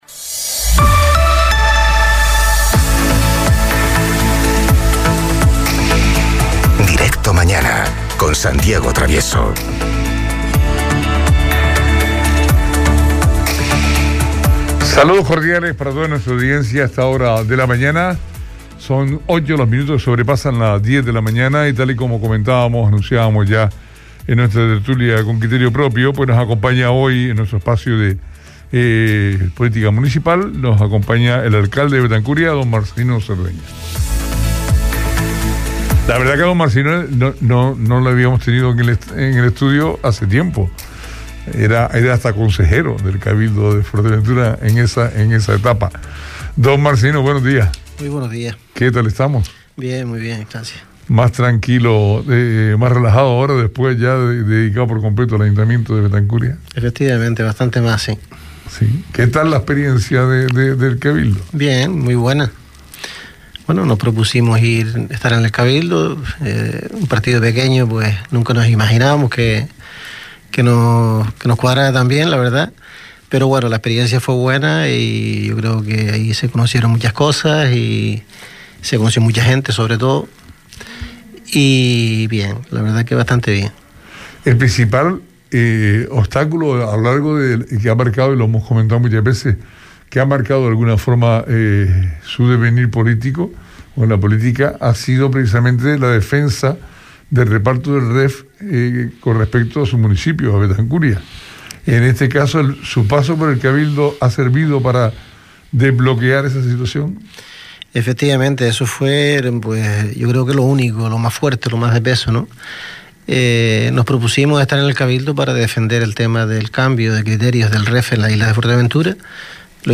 Visita los estudios de la radio hoy Marcelino Cerdeña, alcalde del municipio de Betancuria.